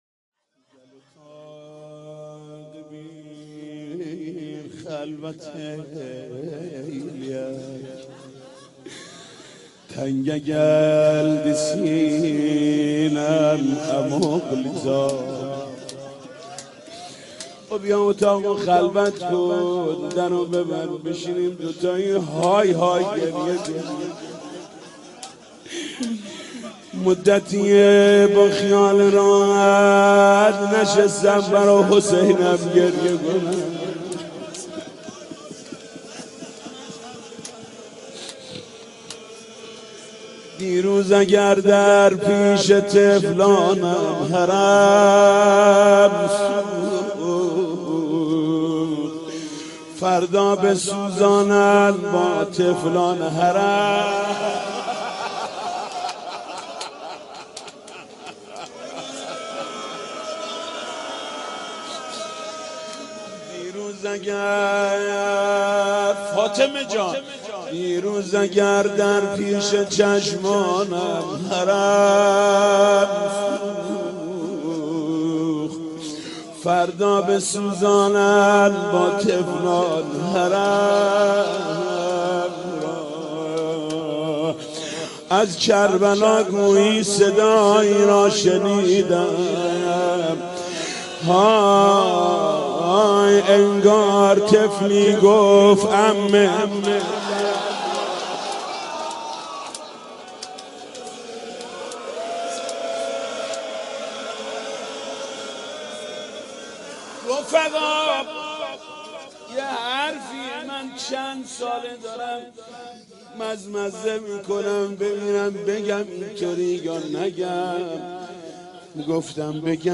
مداحی و نوحه
روضه خوانی در شهادت حضرت فاطمه زهرا(س)